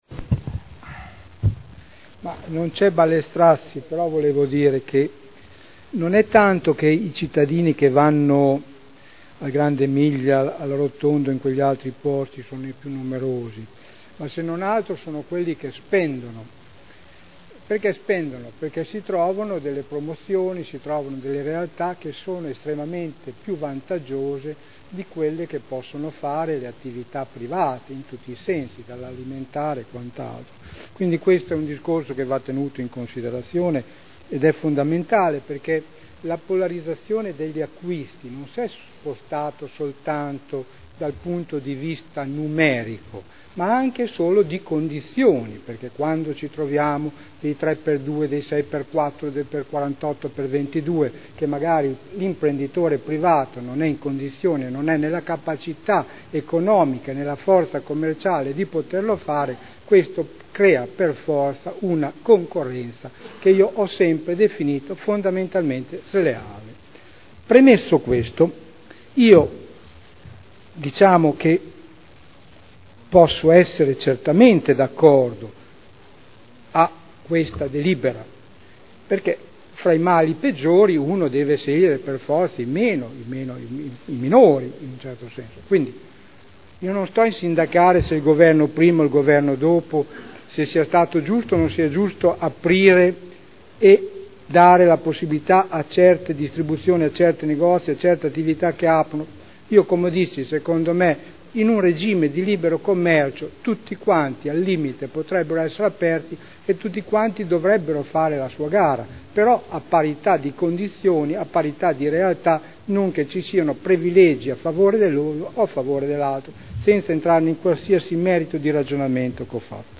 Sergio Celloni — Sito Audio Consiglio Comunale
Seduta del 05/12/2011. Dibattito.